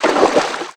High Quality Footsteps / Water
STEPS Water, Walk 12.wav